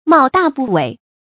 冒大不韪 mào dà bù wěi
冒大不韪发音
成语注音ㄇㄠˋ ㄉㄚˋ ㄅㄨˋ ㄨㄟˇ